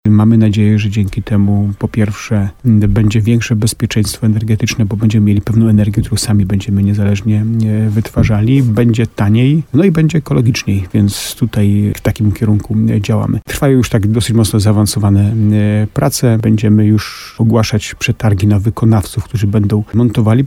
Skorzystają także mieszkańcy, którzy w zeszłym roku zgłosili się do projektu – mówi Jacek Lelek, burmistrz Starego Sącza.